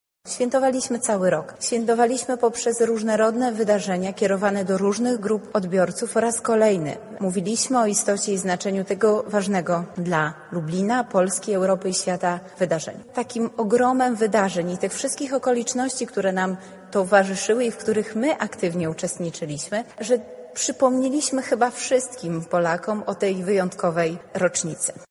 Tak jak z okazji okrągłej rocznicy w 2019 roku, tak i teraz planujemy wiele istotnych wydarzeń – mówi wiceprezydent miasta do spraw Kultury, Sportu i Partycypacji Beata Stepaniuk-Kuśmierzak: